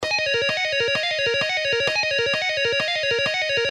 Legato Guitar Exercise
Lessons-Guitar-Mark-Tremonti-Legato-Exercises-3.mp3